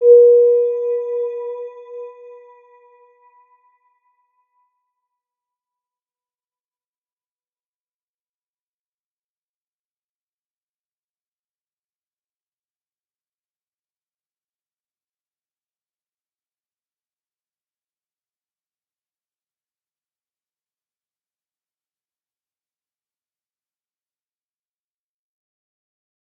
Round-Bell-B4-p.wav